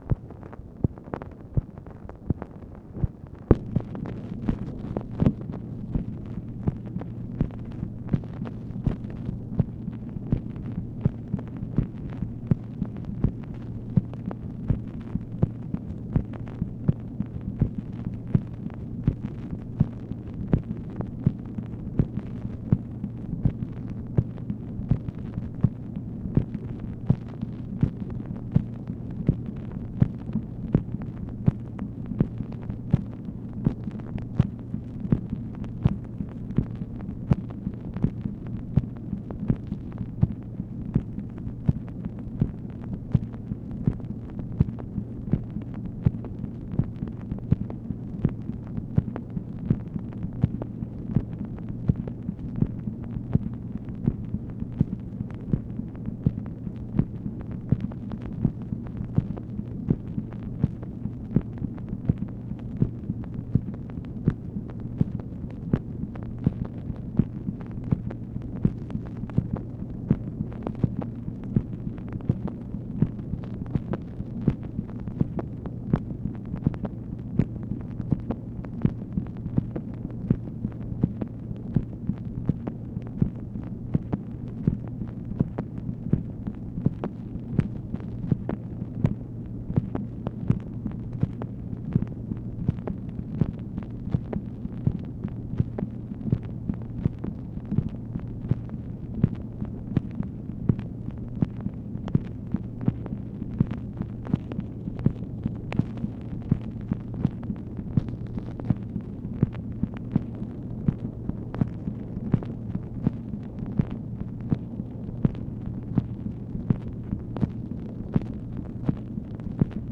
MACHINE NOISE, May 18, 1965
Secret White House Tapes | Lyndon B. Johnson Presidency